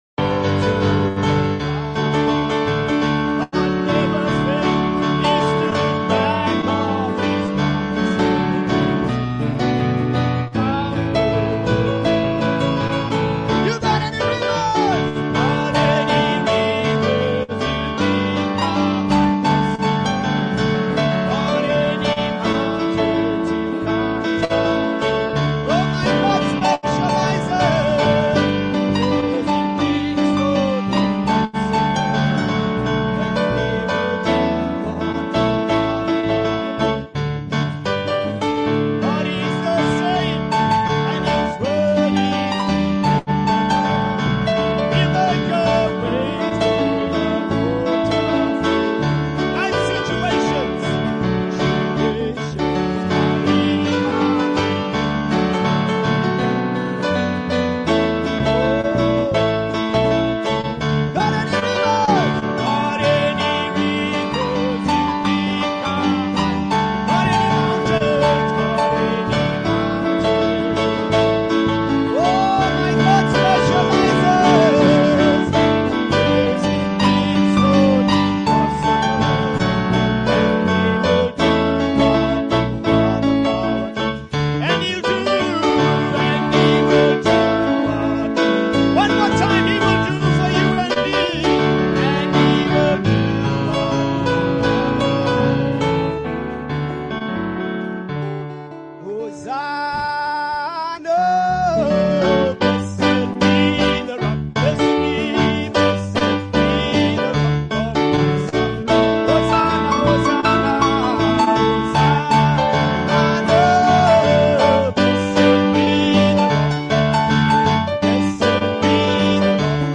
THE TRUE JUBILEE HAS SOUNDED AND WE HAVE RESPONDED Church Services